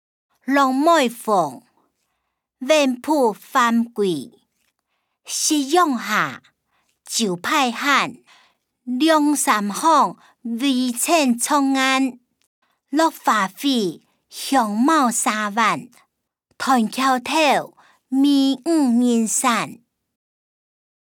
詞、曲-落梅風．遠浦帆歸音檔(饒平腔)